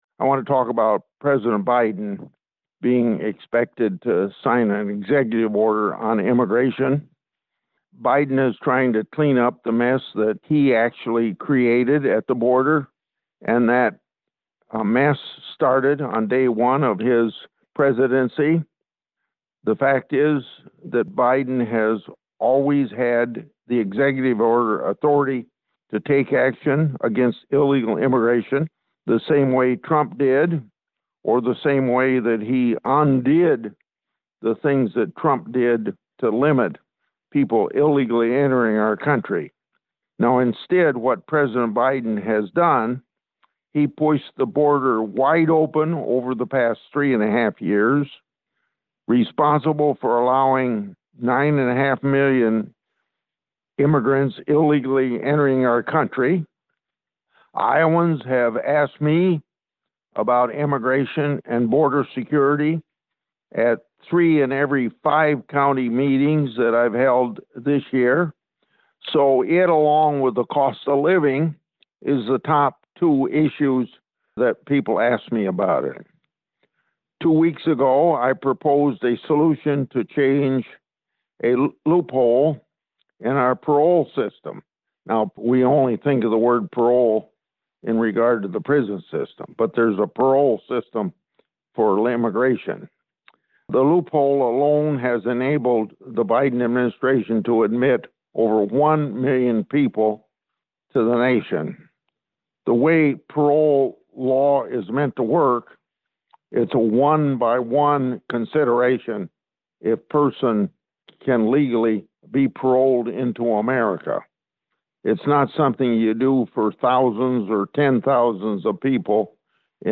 grassley-statement-on-biden-executive-order